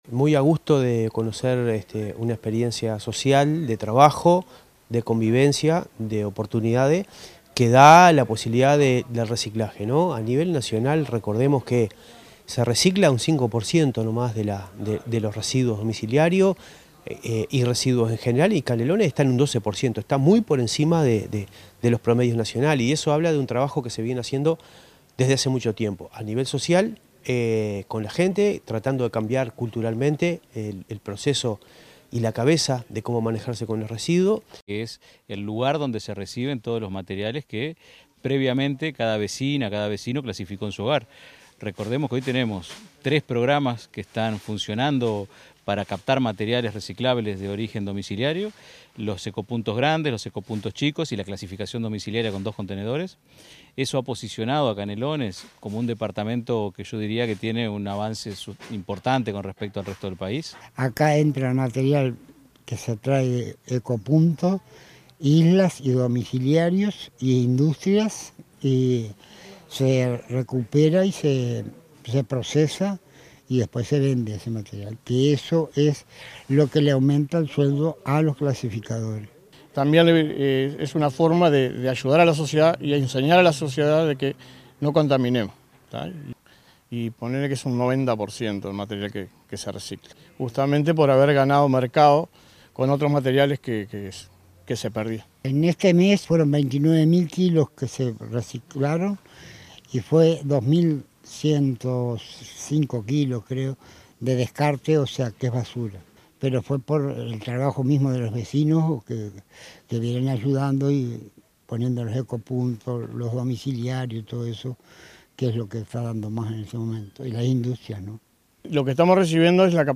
intendente_interino_marcelo_metediera.mp3